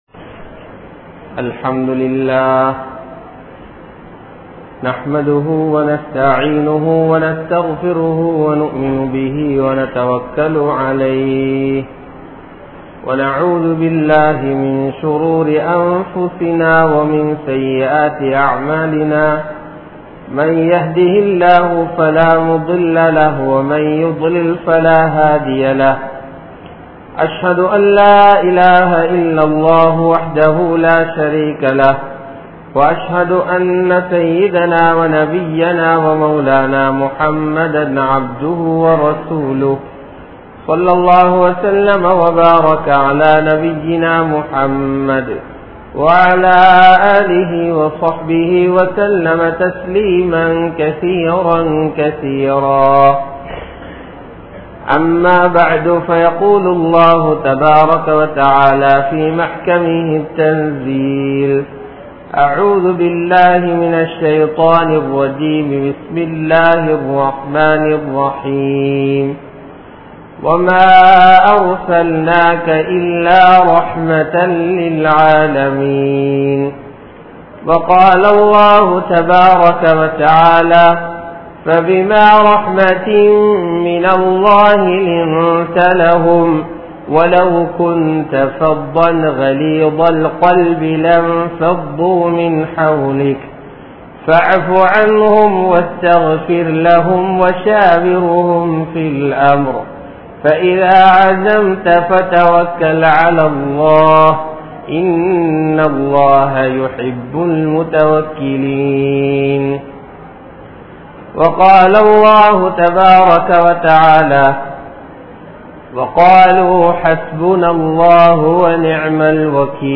Nabi(SAW)Avarhalin Vaalkai Murai (நபி(ஸல்)அவர்களின் வாழ்க்கை முறை) | Audio Bayans | All Ceylon Muslim Youth Community | Addalaichenai